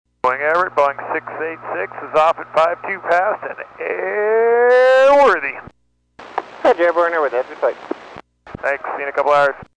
UPS 747-8F N622UP B1 flight. Departure audio